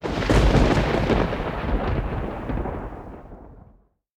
PixelPerfectionCE/assets/minecraft/sounds/ambient/weather/thunder1.ogg at mc116
thunder1.ogg